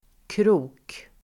Uttal: [kro:k]